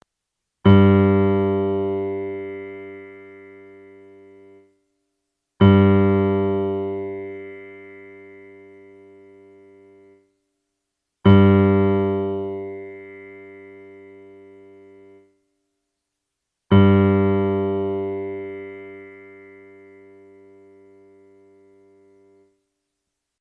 Wenn Sie auf die folgenden Links klicken, hören Sie, wie die Saiten klingen und können ihre Violoncello danach stimmen:
G-Saite (mp3):
cello_g.mp3